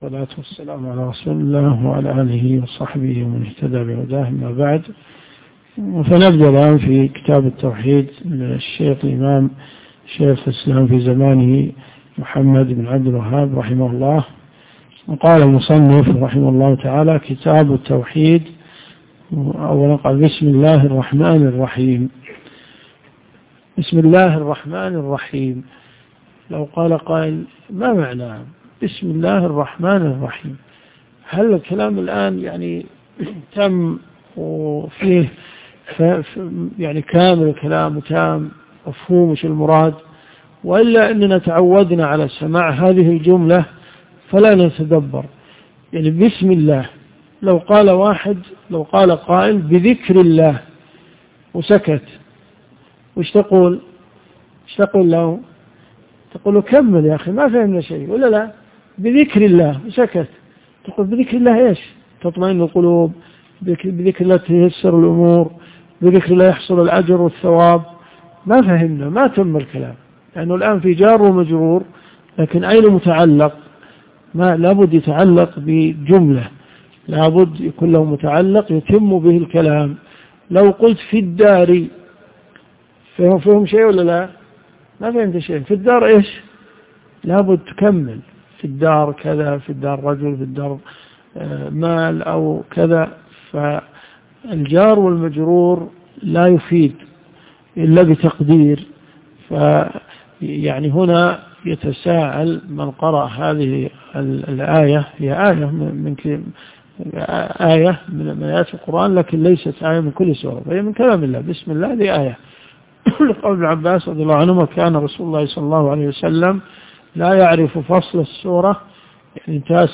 الرئيسية الكتب المسموعة [ قسم التوحيد ] > كتاب التوحيد ( 1 ) .